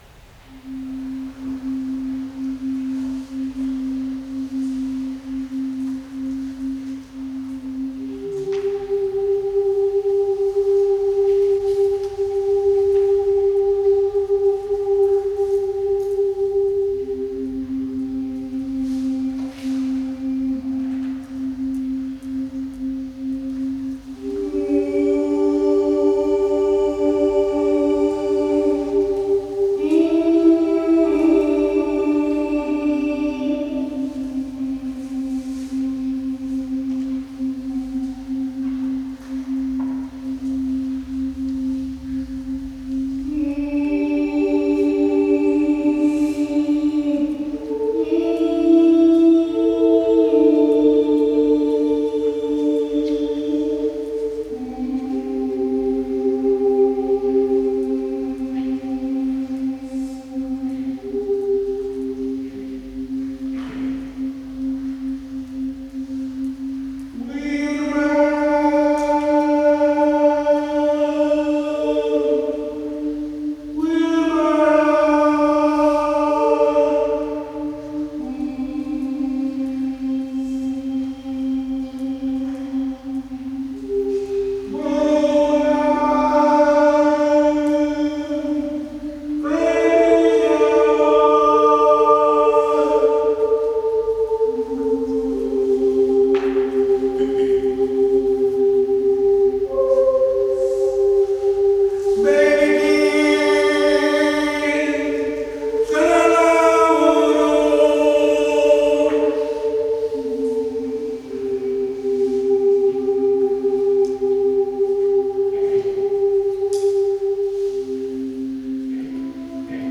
Whirlies; manikay; shakuhachi; viola; violin; Biak song
rich voice singing